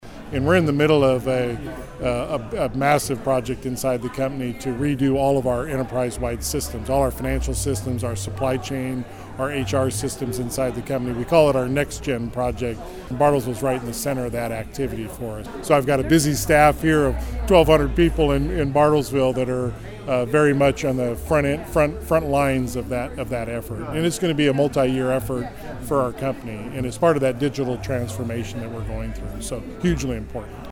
Lance was the guest speaker during a Bartlesville Chamber of Commerce forum on Tuesday at CityChurch.